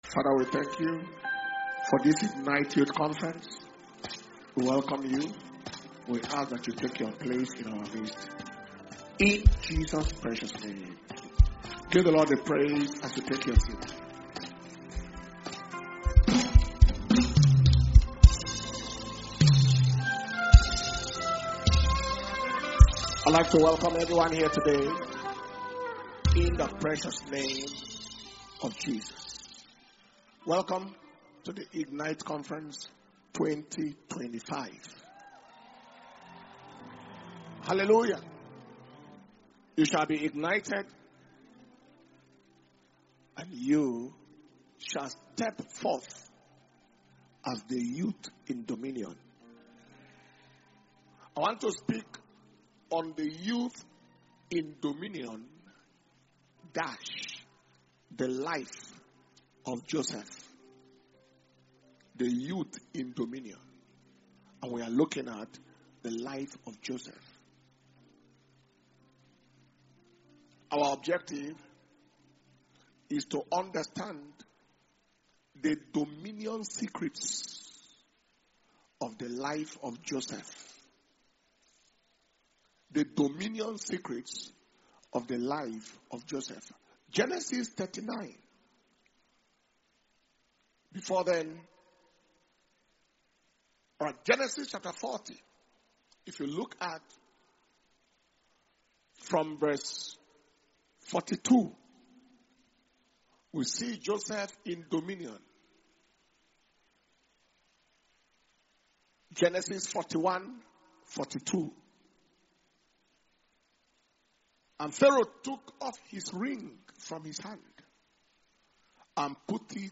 Dunamis Ignite Conference Monday August 4th 2025 – Day One Evening Session